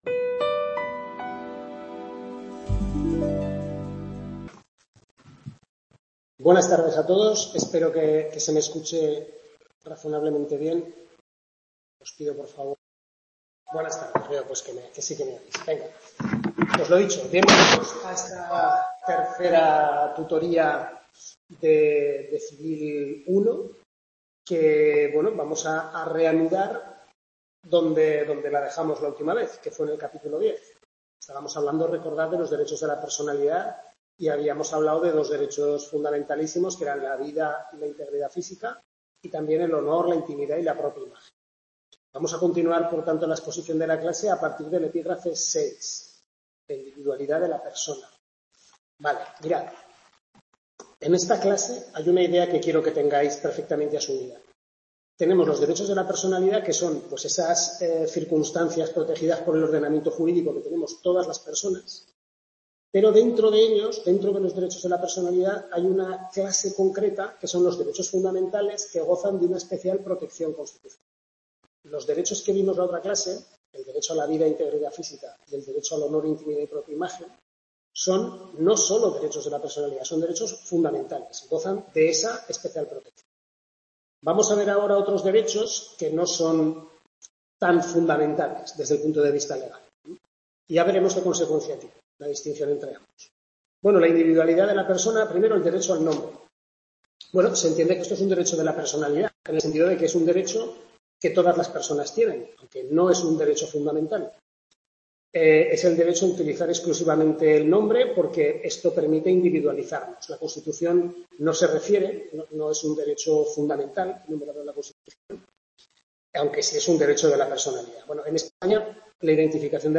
Tutoría 3/6 primer cuatrimestre Civil I (Parte General y Persona), centro UNED Calatayud, capítulos 10-13 del Manual del Profesor Lasarte